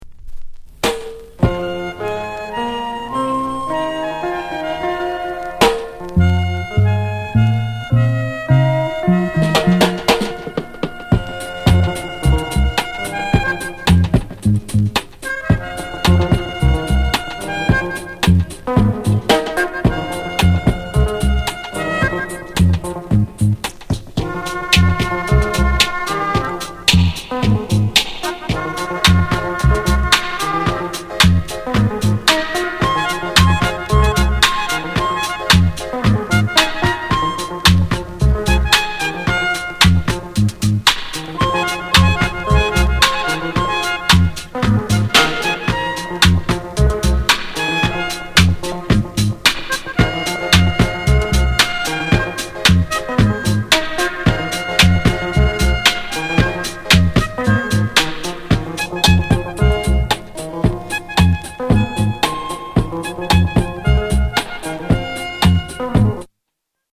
WICKED ROOTS INST